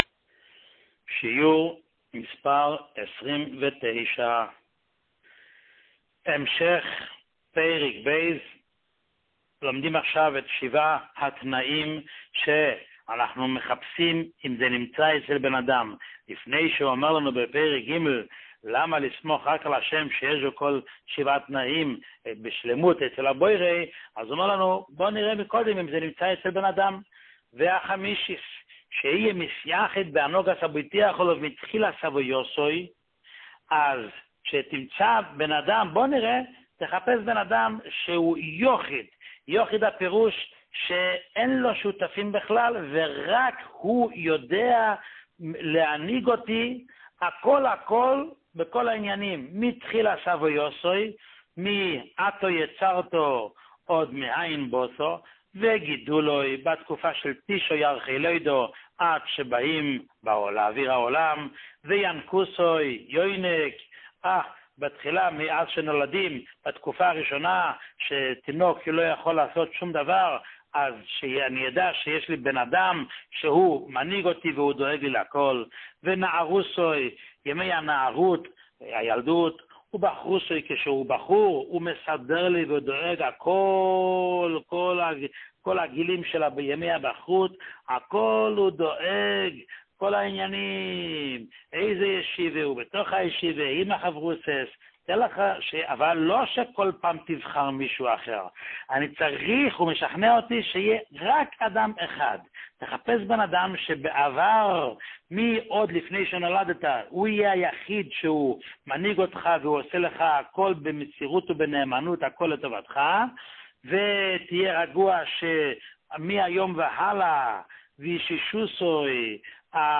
שיעורים מיוחדים
שיעור 29